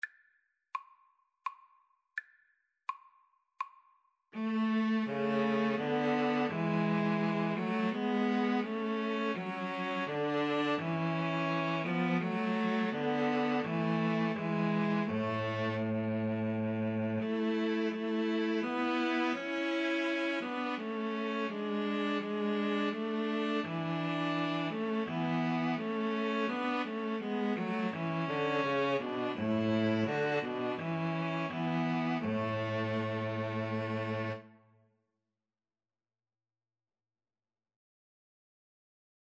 3/4 (View more 3/4 Music)
Piano Trio  (View more Easy Piano Trio Music)